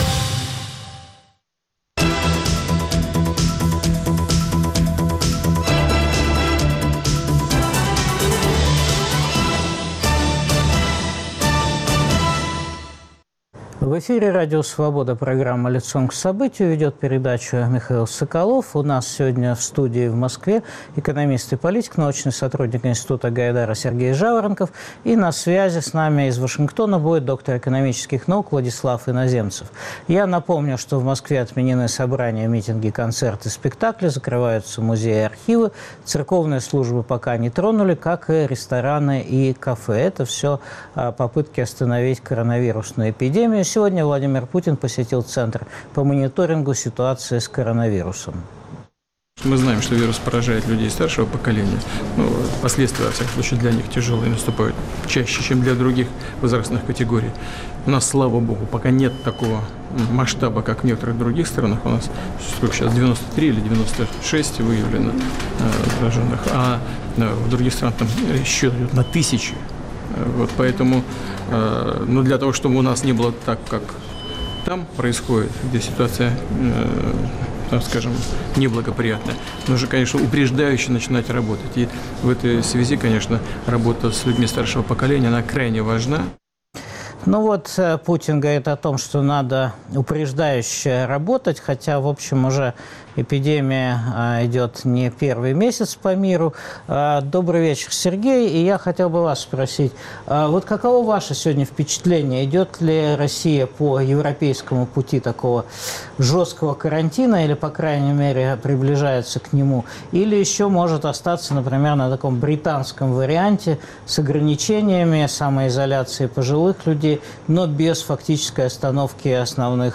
Каковы будут последствия нынешнего коронавирусного катаклизма для мировой и российской экономики? Обсуждают экономист и политик